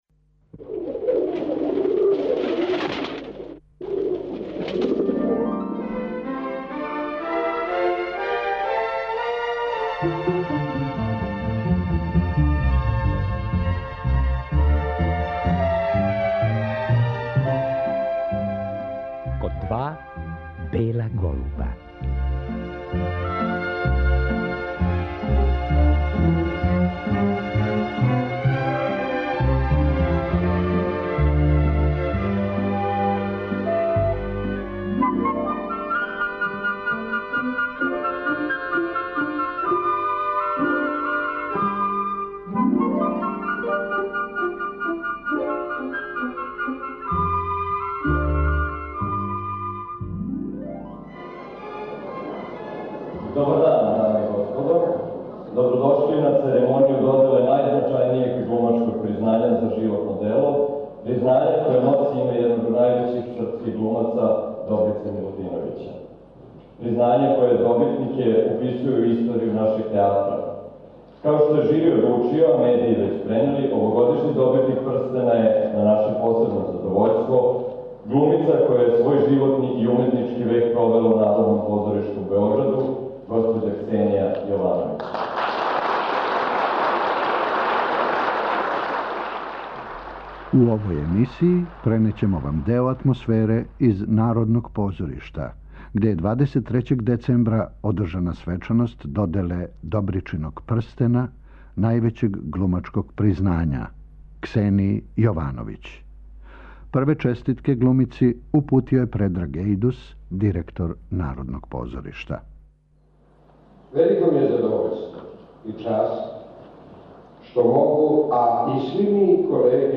IN MEMORIAM - У овој емисији пренећемо вам део атмосфере из Народног позоришта у Београду где је 23. децембра 2007. године одржана свечаност доделе Добричиног прстена, највећег глумачког признања - Ксенији Јовановић.